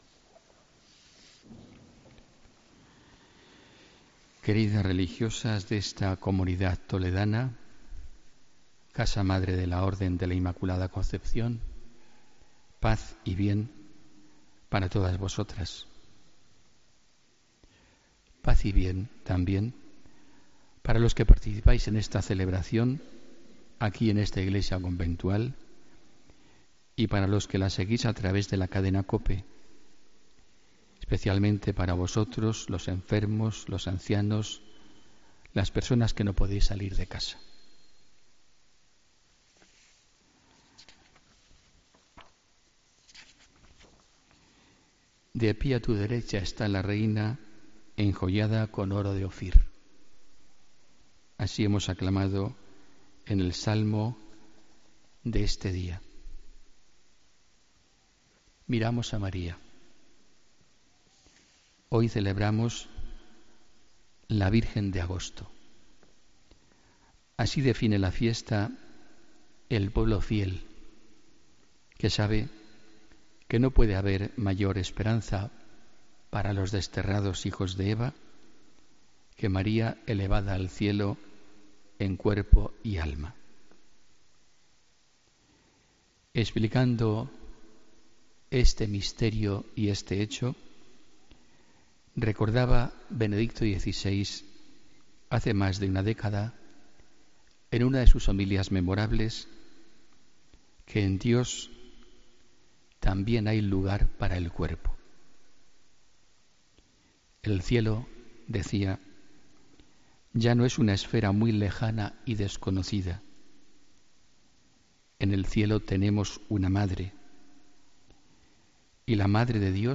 Homilía 15 de agosto de 2017